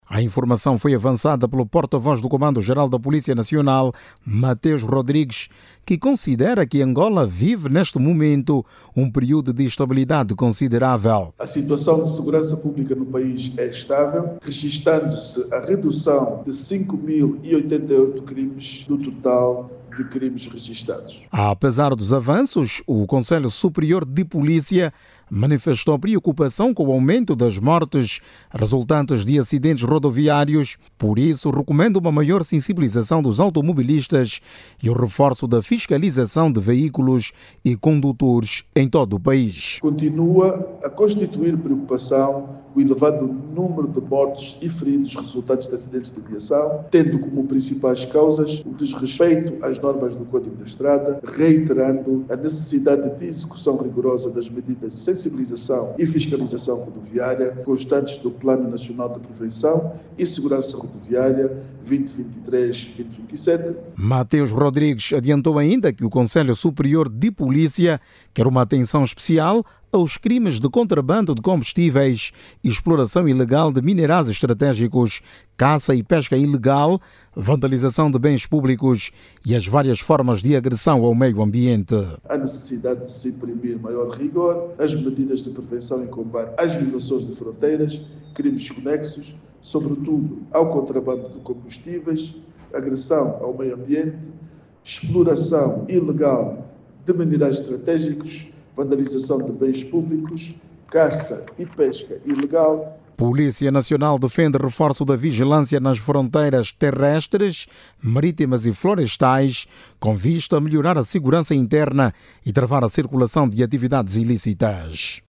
Saiba mais dados no áudio abaixo com o repórter